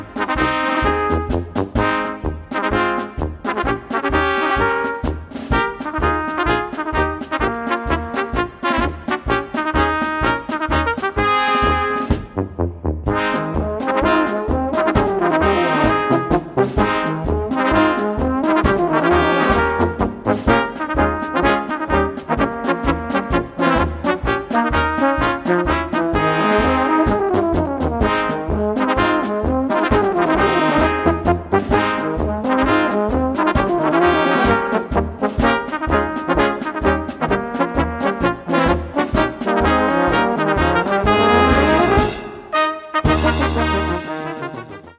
Gattung: für Tenohorn und Bariton in B
Besetzung: Instrumentalnoten für Tenorhorn